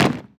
foot2.wav